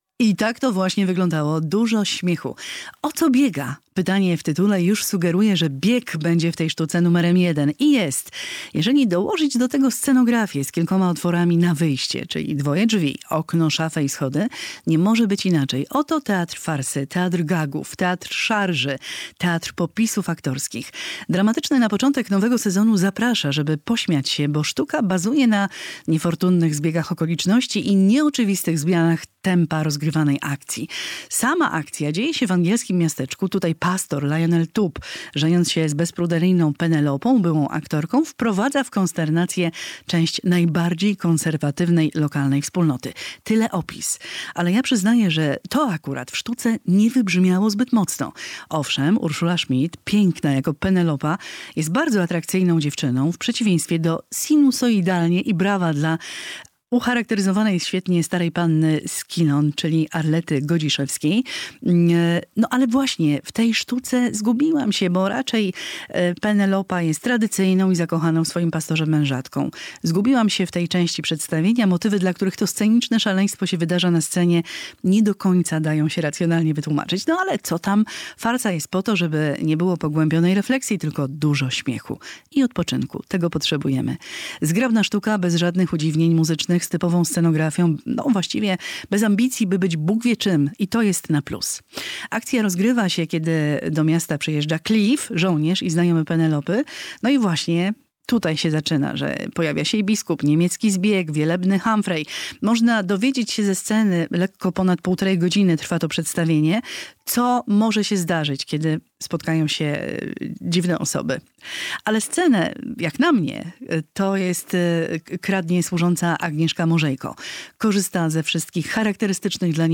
Spektakl "O co biega?" - recenzja